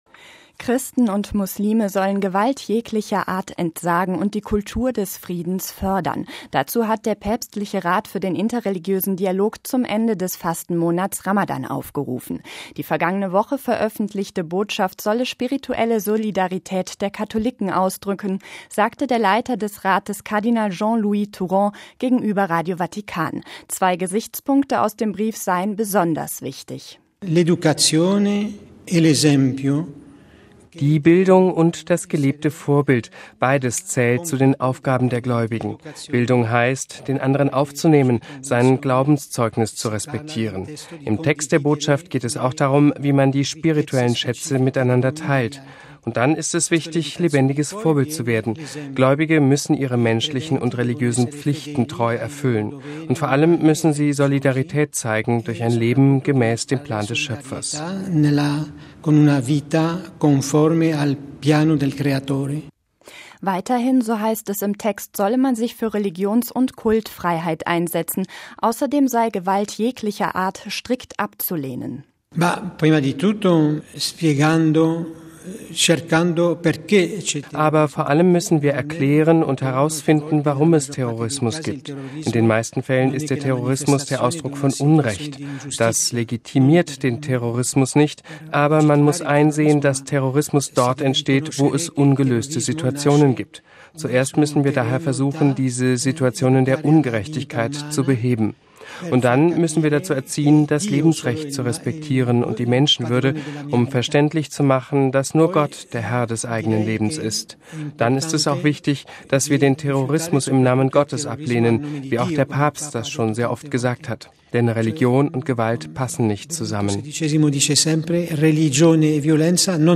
Dazu hat der päpstliche Rat für den interreligiösen Dialog zum Ende des Fastenmonats Ramadan aufgerufen. Die vergangene Woche veröffentlichte Botschaft solle spirituelle Solidarität der Katholiken ausdrücken, sagt der Leiter des Rates, Kardinal Jean-Louis Tauran gegenüber Radio Vatikan.